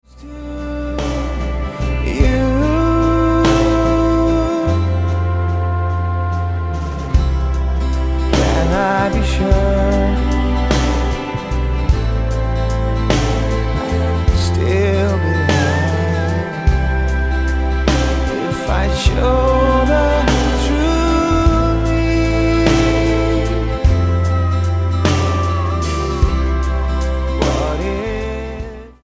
A pop rock band from Norway sharing God's love
Style: Pop